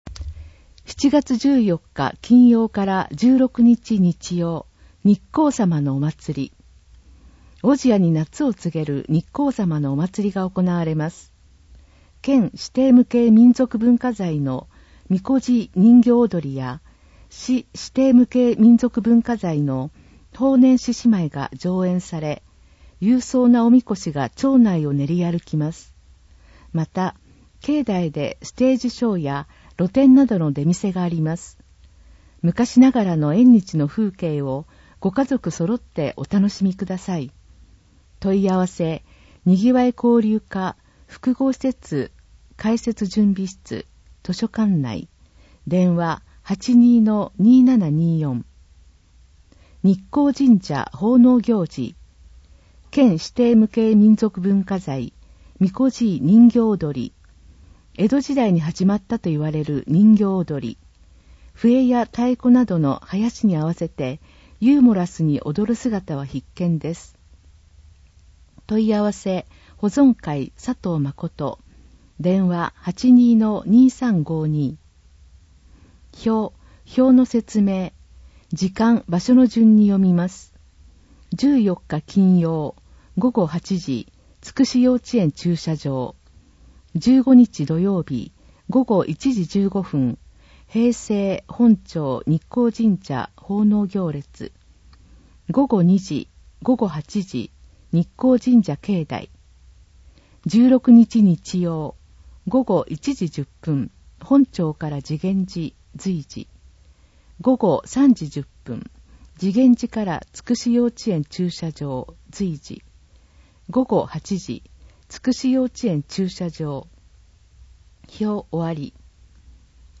令和5年度広報おぢやの音声訳（MP3） - 小千谷市ホームページ
現在、広報おぢや、社協だより、小千谷新聞の音声訳を行い、希望する方へ無料で音声訳CDをお届けしています。